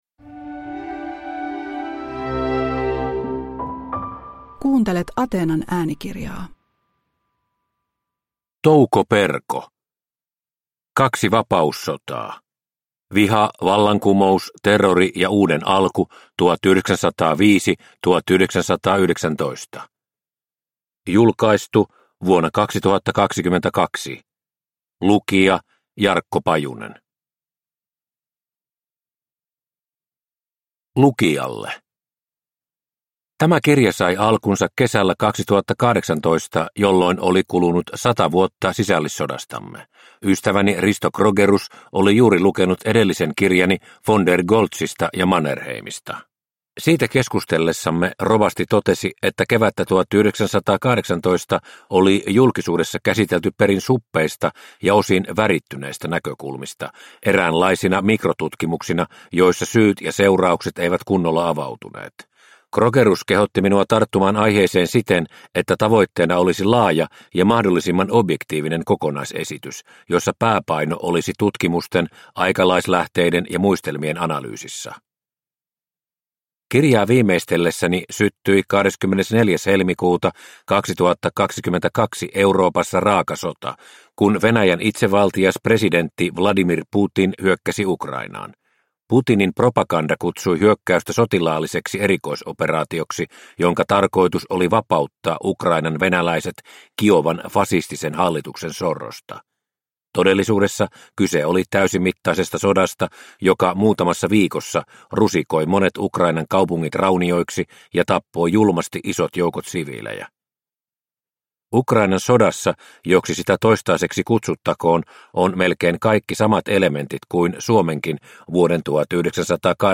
Kaksi vapaussotaa – Ljudbok – Laddas ner